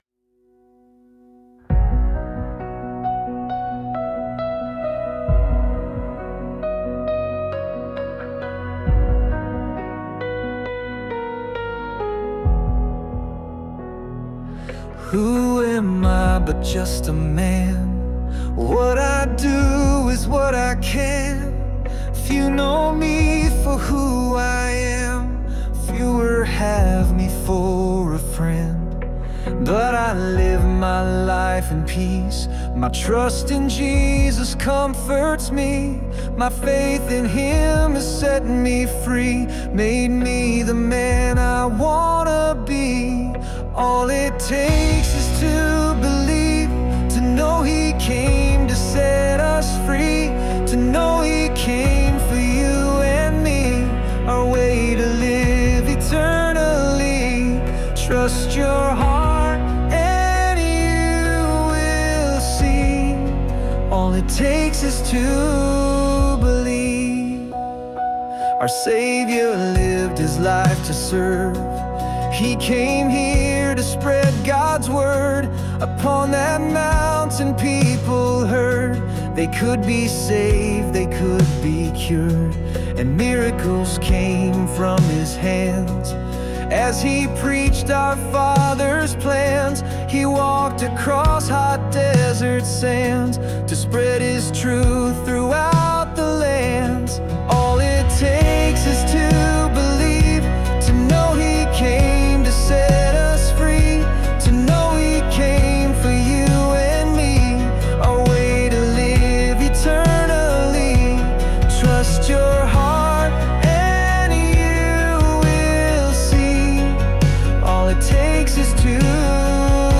Complete Demo Song, with lyrics and music